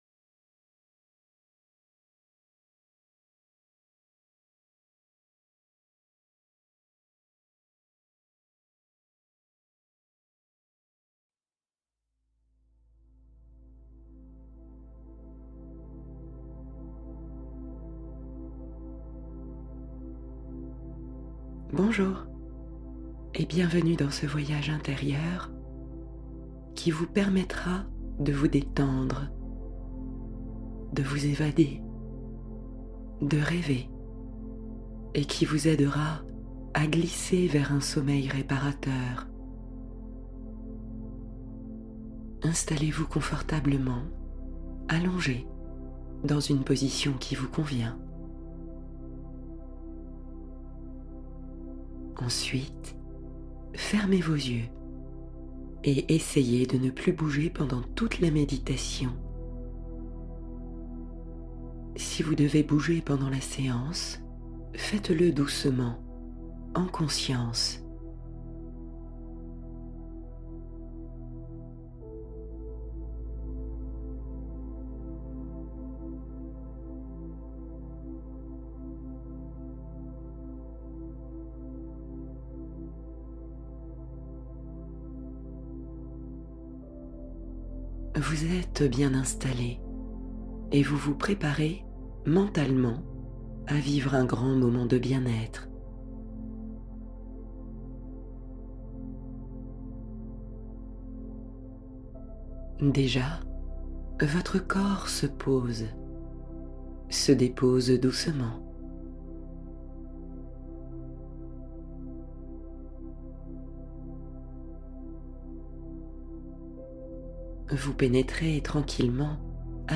Apaiser le cœur : méditation douce de réconfort